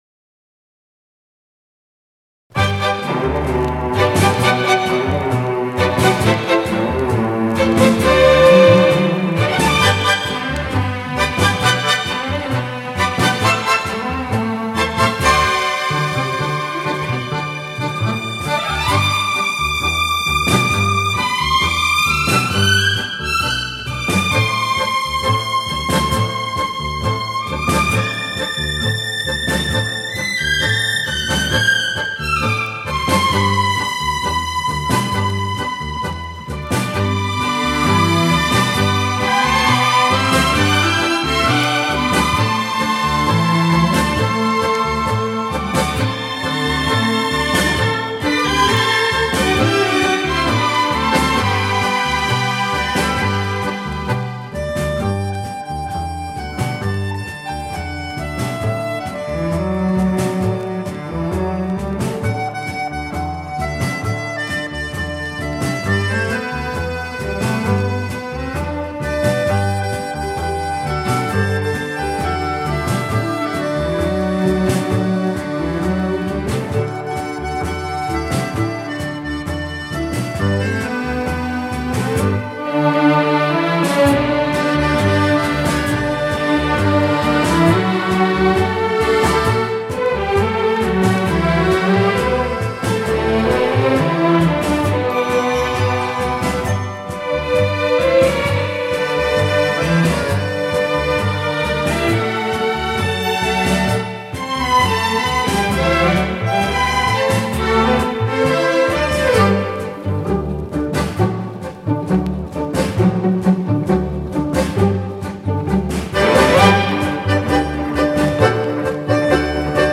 Скрипочки - есть, а вот  с бек-вокалом напряженка...  придется  подпевать
Это в мажоре (быстрый темп), а та была медленная, такая, как примерно в 60-е  в японском стиле.